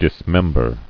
[dis·mem·ber]